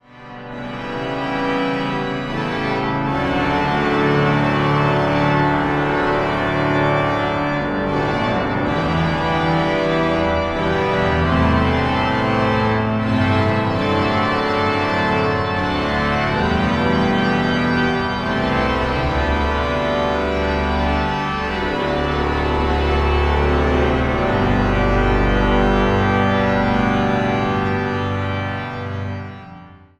orgel in l'Abbatiale Saint Ouen in Rouen